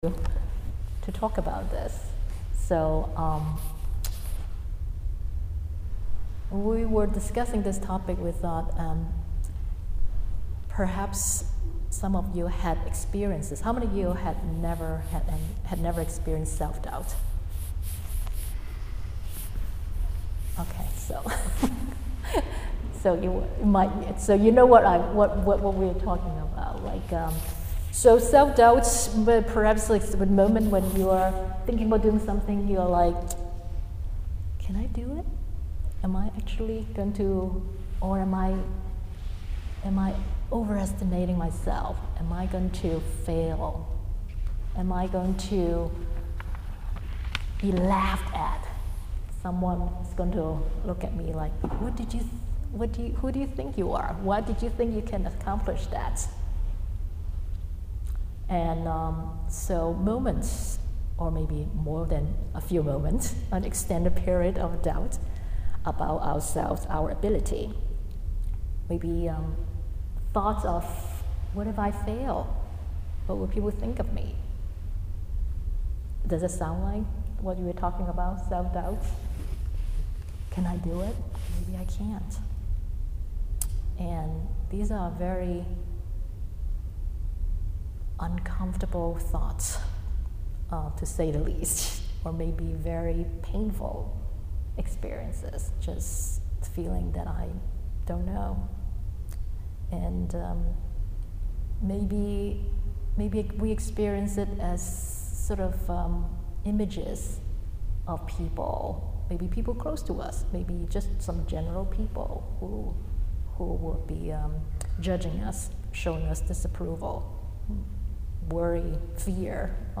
This talk was given at the meditation workshop hosted by the Buddhist Sangha of Yale University on November 27, 2018.